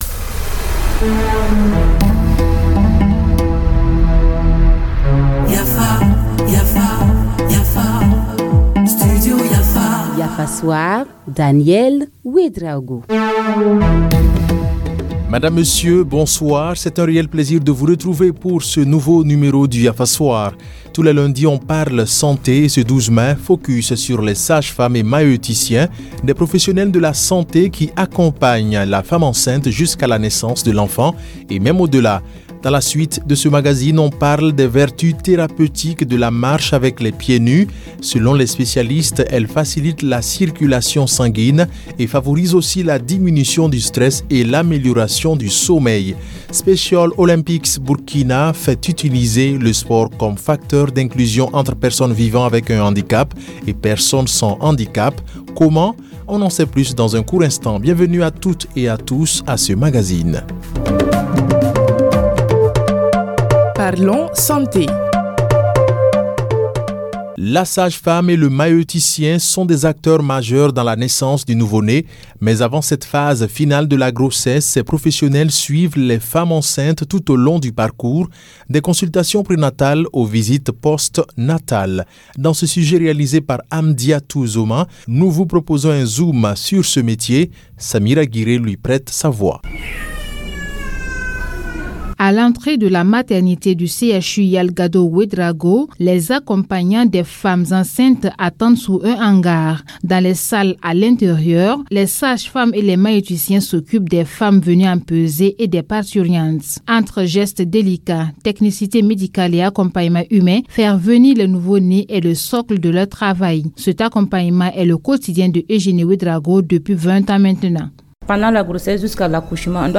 Reportage: La sage-femme et le maïeuticien des acteurs majeurs dans la naissance du nouveau-né